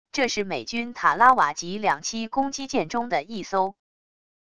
这是美军塔拉瓦级两栖攻击舰中的一艘wav音频生成系统WAV Audio Player